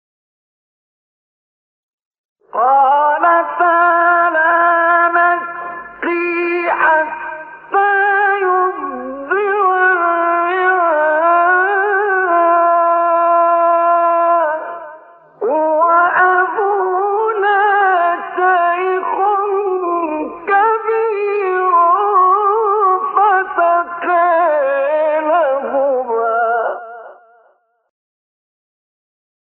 سایت-قرآن-کلام-نورانی-نهاوند-مصطفی-اسماعیل-2.mp3